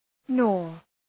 Shkrimi fonetik {nɔ:r}